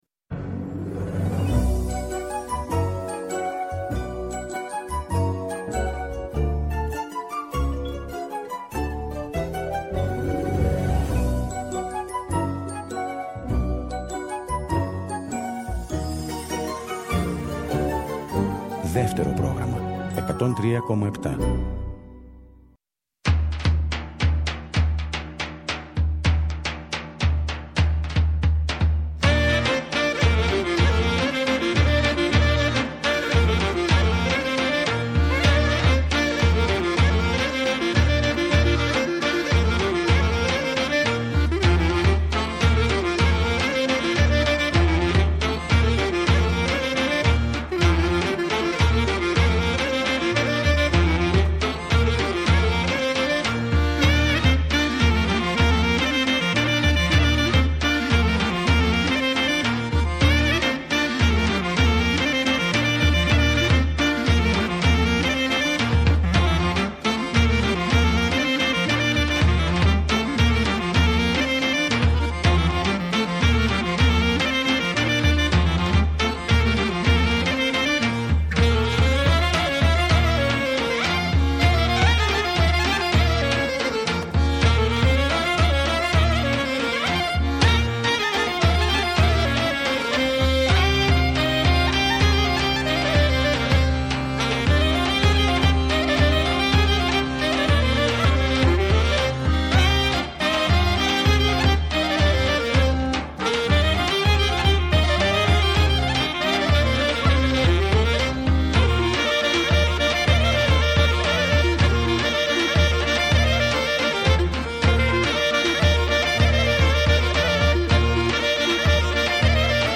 Η παραδοσιακή μας μουσική, το δημοτικό τραγούδι, οι αγαπημένοι μας δημιουργοί από το παρελθόν, αλλά και η νεότερη δραστήρια γενιά των μουσικών, συνθέτουν ένα ωριαίο μουσικό παραδοσιακό γαϊτανάκι.